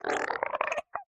sounds / mob / strider / idle5.ogg